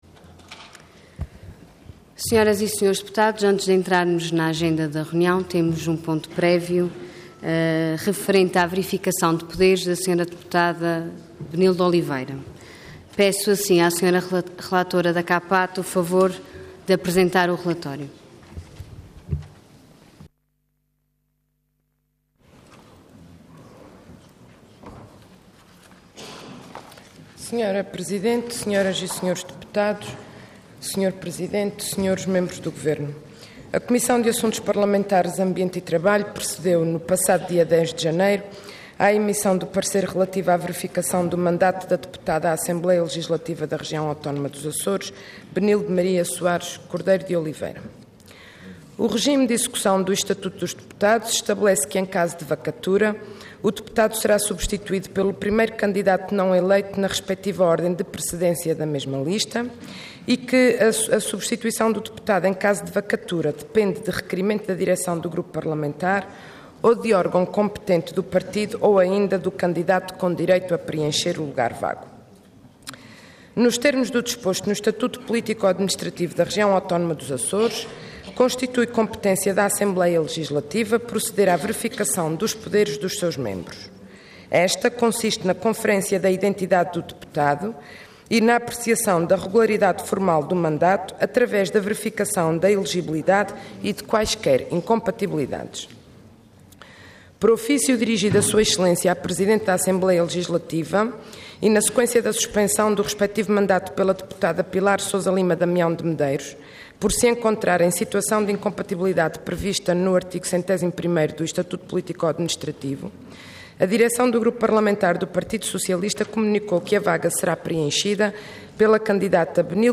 Assembleia Legislativa da Região Autónoma dos Açores
Intervenção
Isabel Rodrigues
Relatora